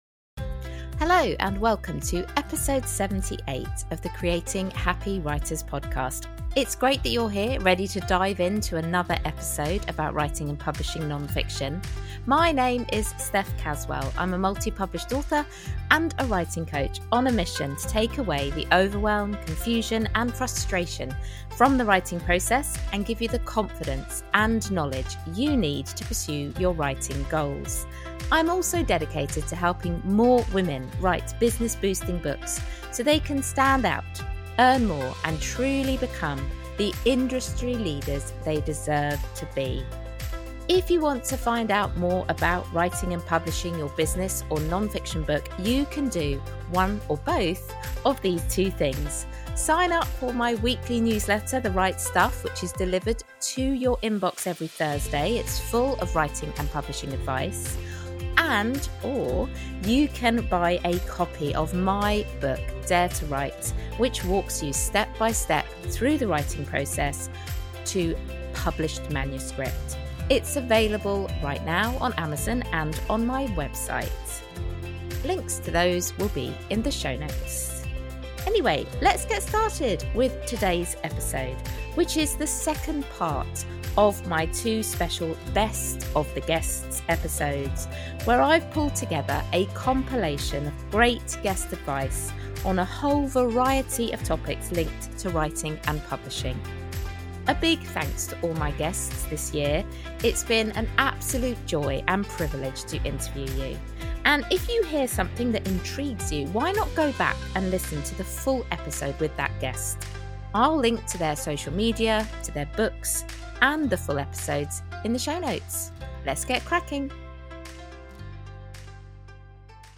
In this second part of my special compilation episode, six incredible authors and publishing professionals share their honest experiences and practical wisdom.